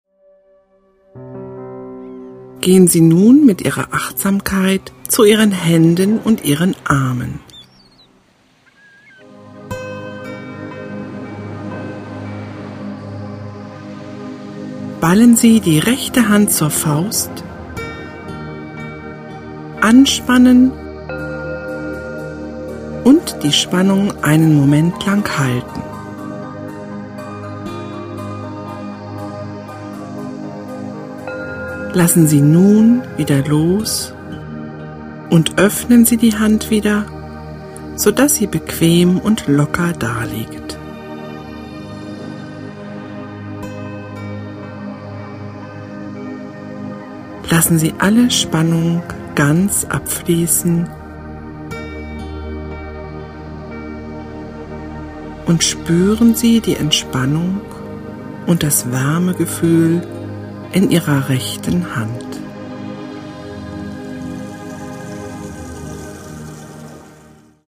Musik: N.N.